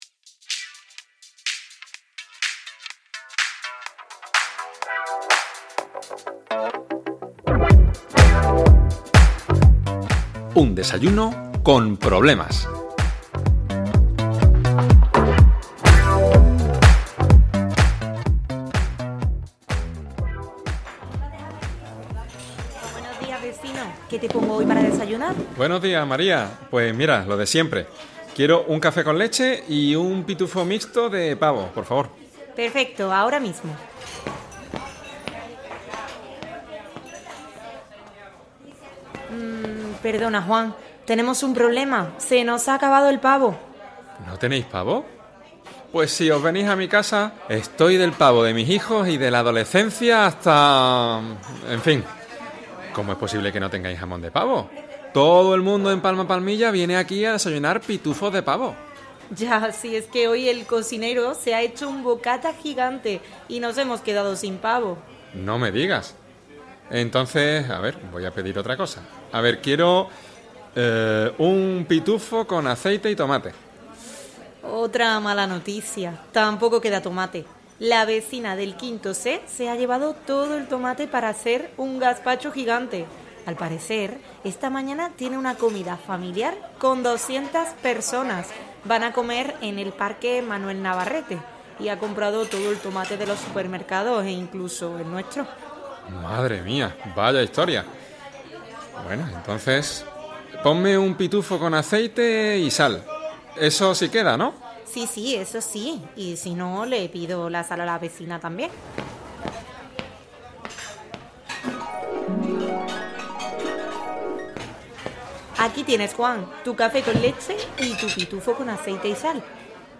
• Este es un listening para aprender español, nivel básico. La historia dura 2 minutos; el resto son preguntas y otros datos.